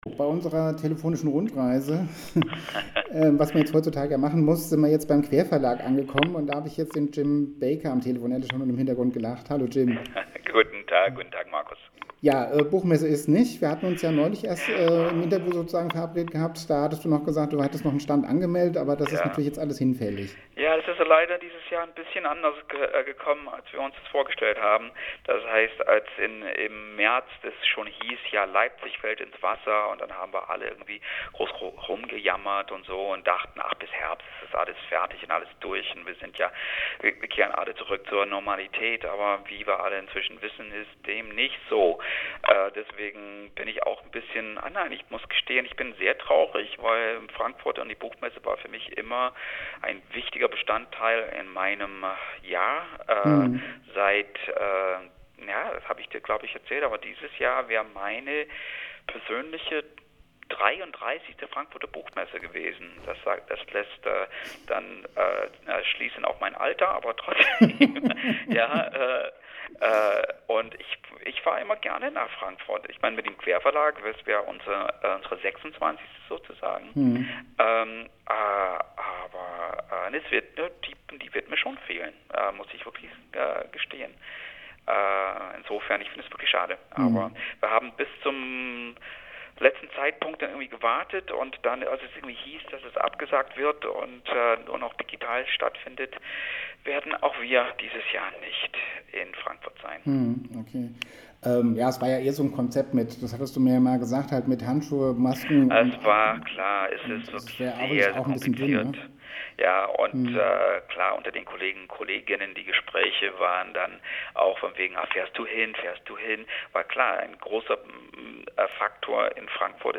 Der Querverlag auf der Buchmesse